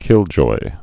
(kĭljoi)